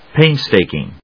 pains・tak・ing /péɪnztèɪkɪŋ/